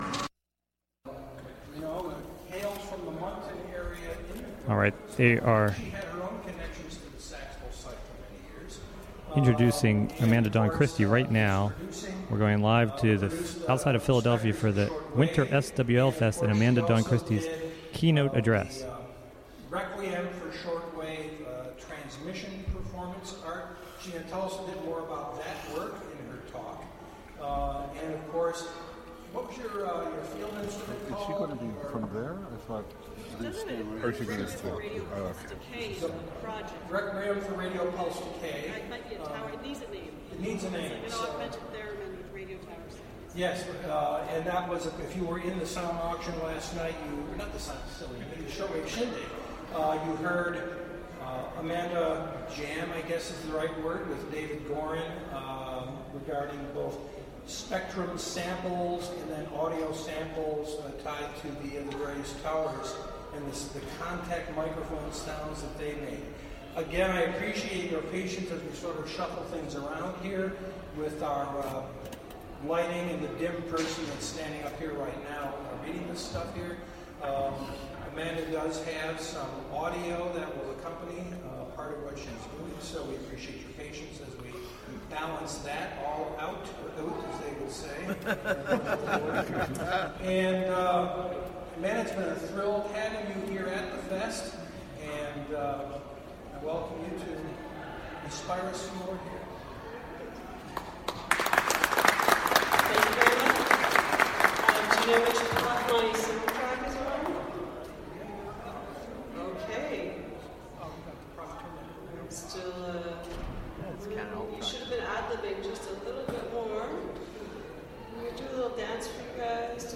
Winter SWL Fest Keynote
Live from the Winter SWL Fest